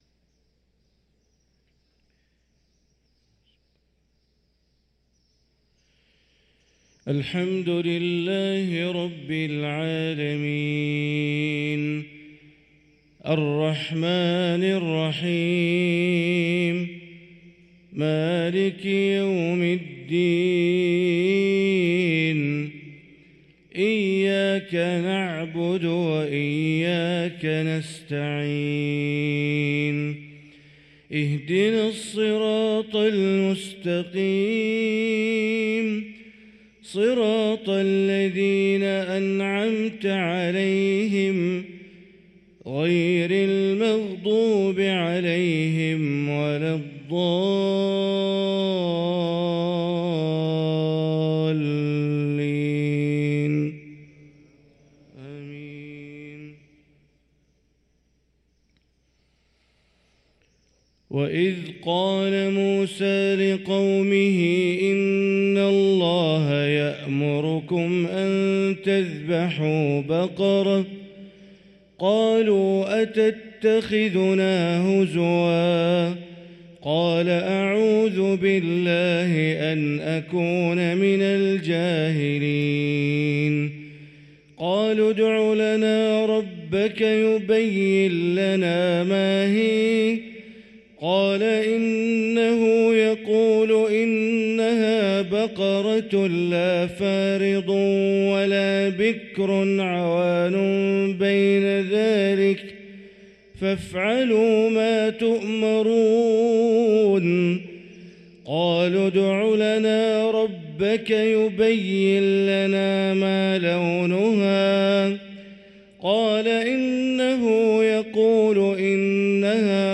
صلاة الفجر للقارئ بندر بليلة 9 رجب 1445 هـ